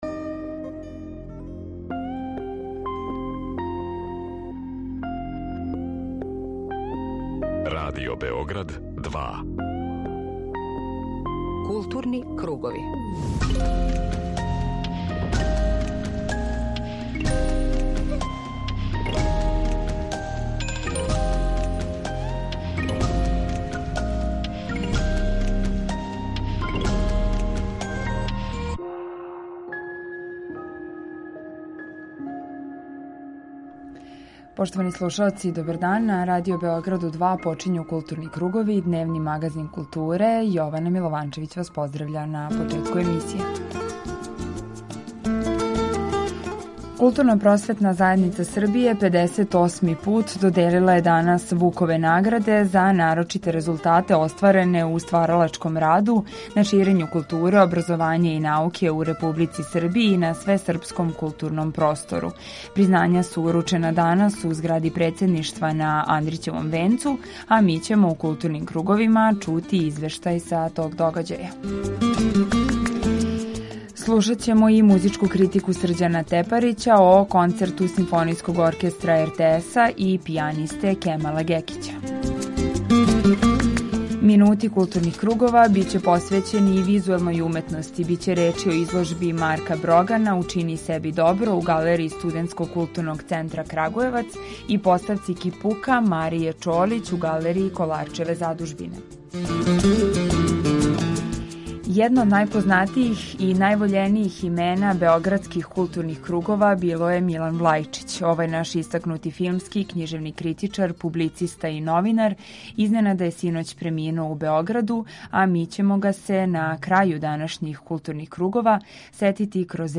Група аутора Централна културно-уметничка емисија Радио Београда 2.